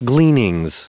Prononciation du mot gleanings en anglais (fichier audio)
Prononciation du mot : gleanings